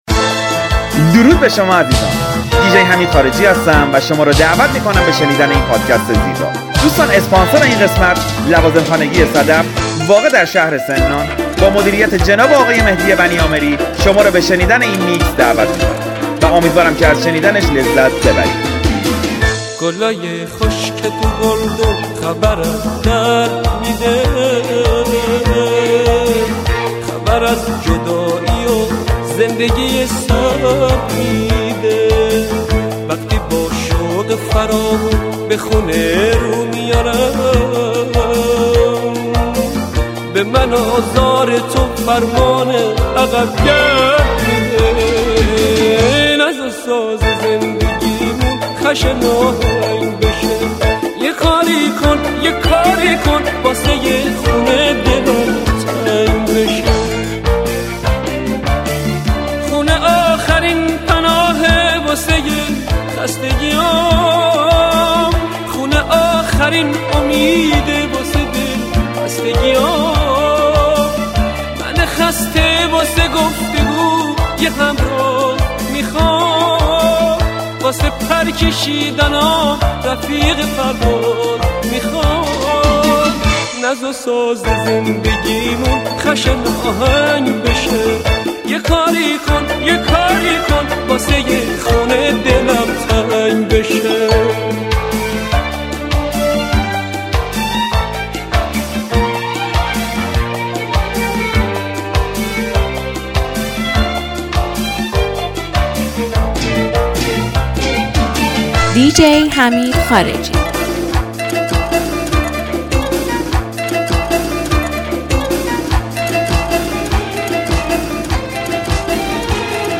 کالکشن آهنگ‌های ترکیبی
موزیک ریمیکس